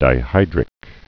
(dī-hīdrĭk)